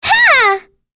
Cleo-Vox_Attack4.wav